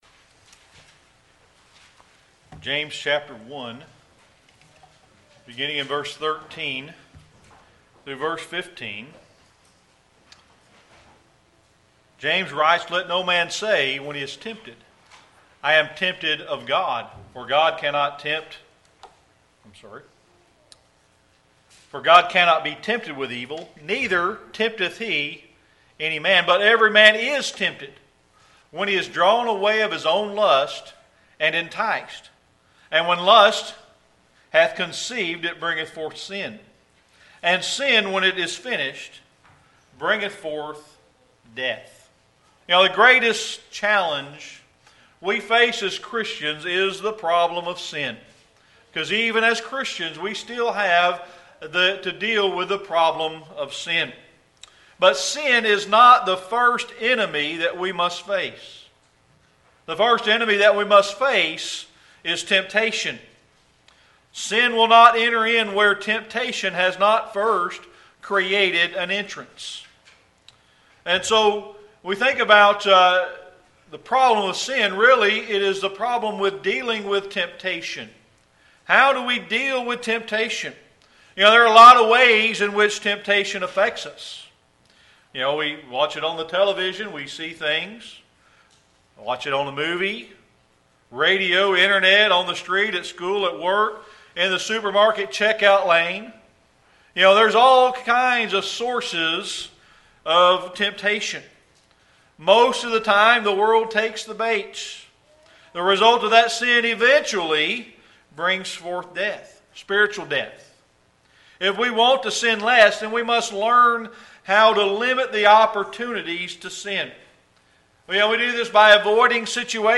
Sermon Archives Passage: Proverbs 4:24-27 Service Type: Sunday Evening Worship We must deal with the problem of sin